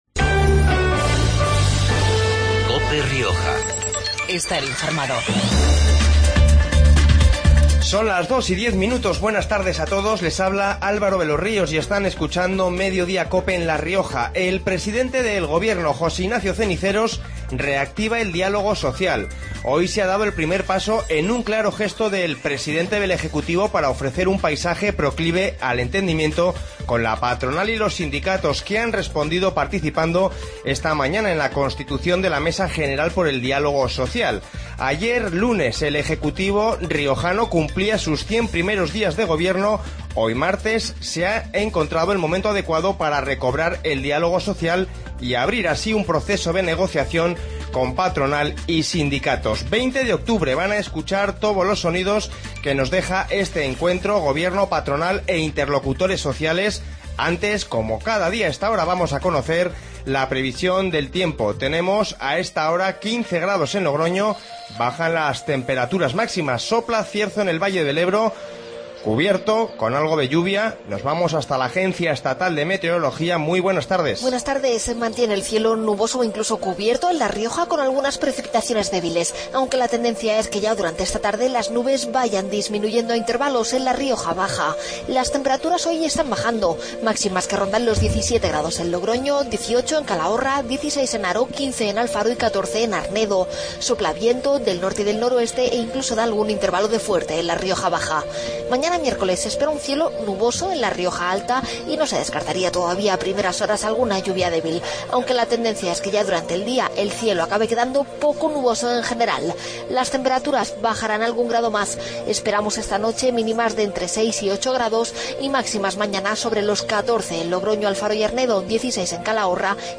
Informativo Mediodia en La Rioja 20-10-15